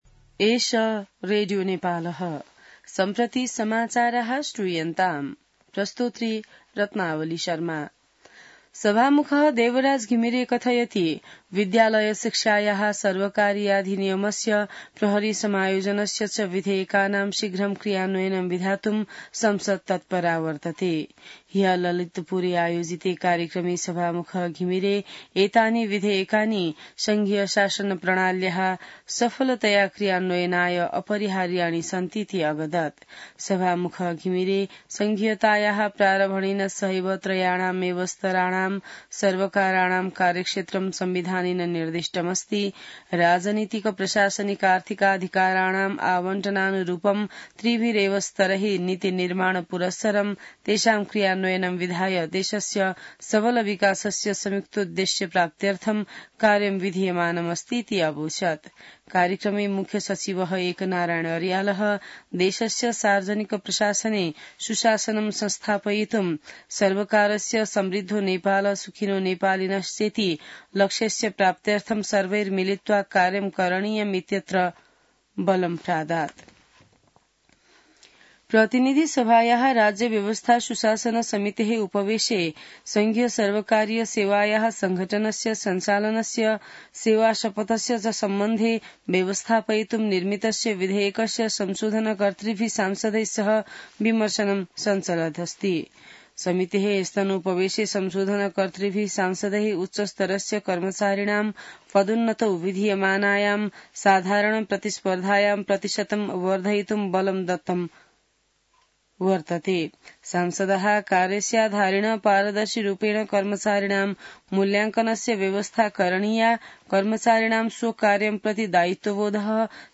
संस्कृत समाचार : ६ पुष , २०८१